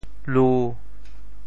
潮州发音 潮州 lu1